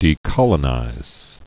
(dē-kŏlə-nīz)